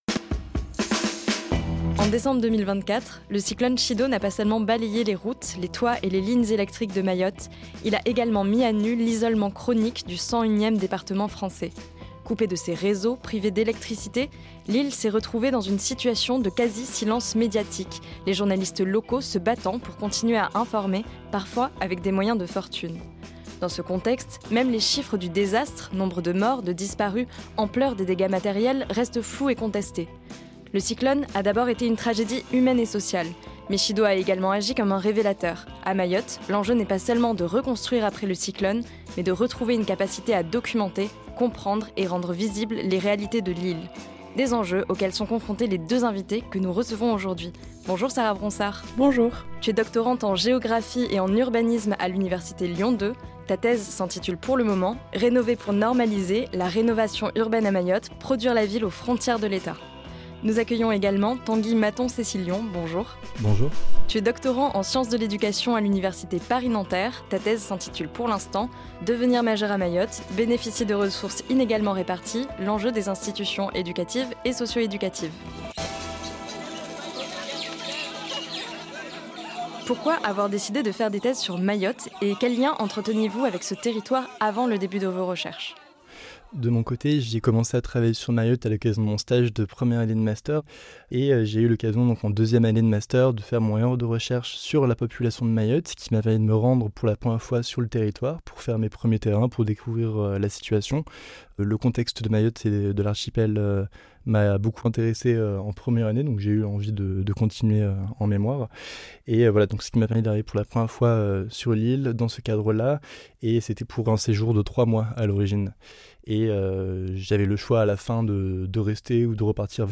Des enjeux auxquels sont confrontés nos deux invité·es pour ce nouvel épisode de Sous-terrain (Saison 2), le podcast qui dévoile les coulisses de l'enquête de terrain:
Type Entretien